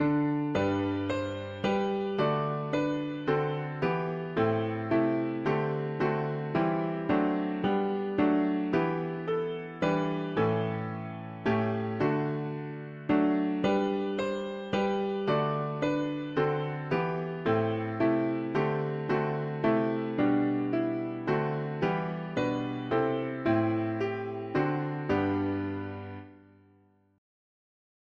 We t… english christian 4part evening
Key: G major